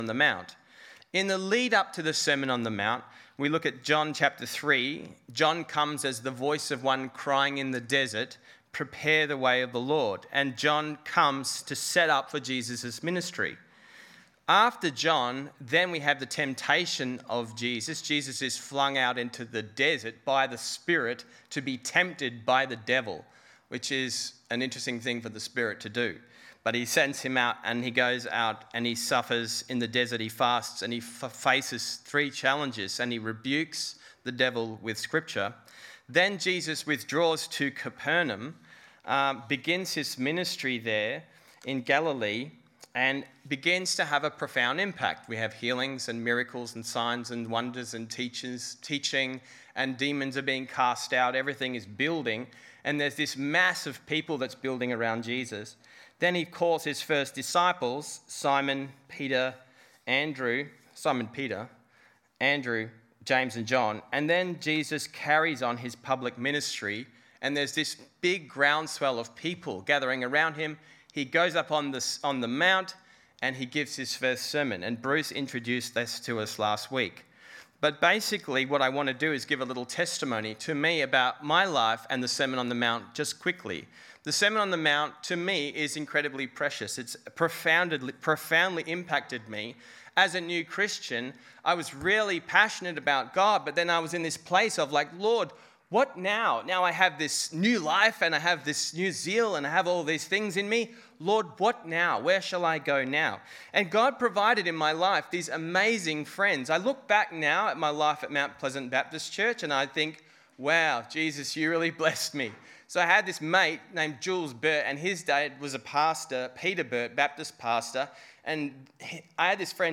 Passage: Matthew 5:17-32 Service Type: Sunday 10 am